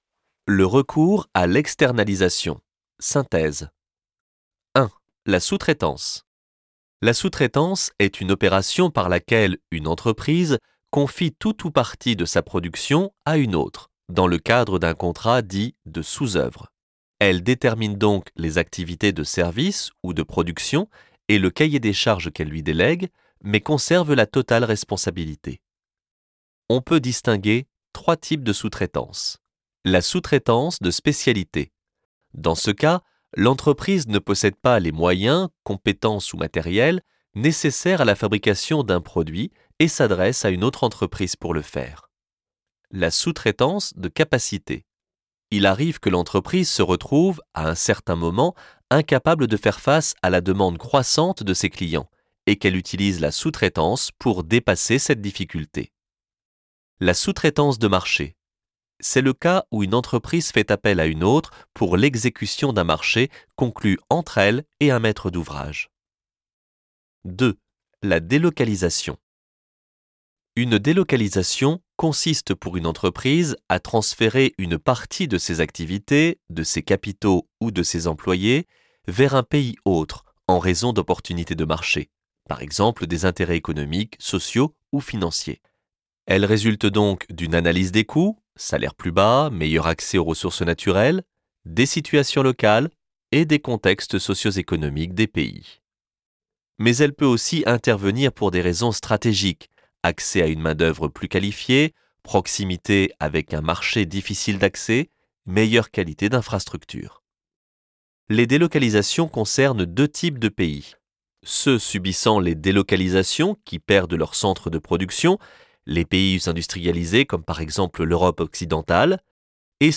Synthèse audio Chapitre 19 - Le recours à l'externalisation